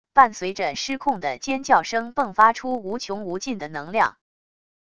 伴随着失控的尖叫声迸发出无穷无尽的能量wav音频